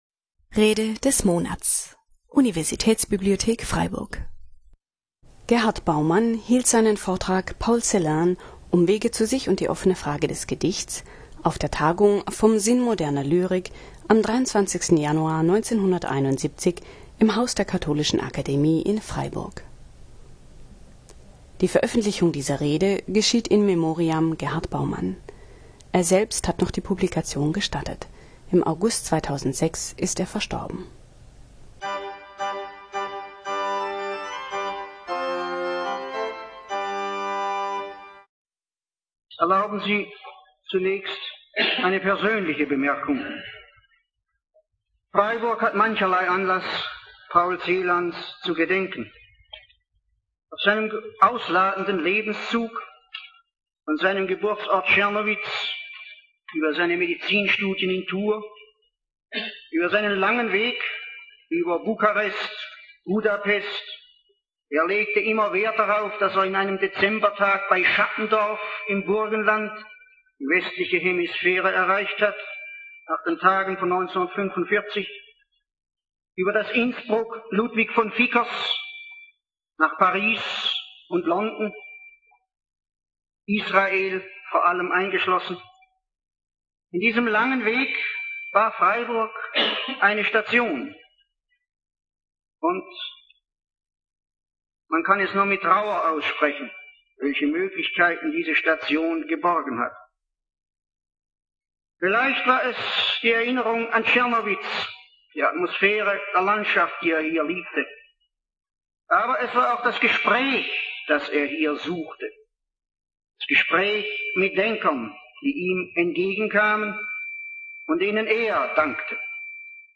Vortrag
auf der Tagung "Vom Sinn moderner Lyrik" am 23. Januar 1971 im Haus der Katholischen Akademie in Freiburg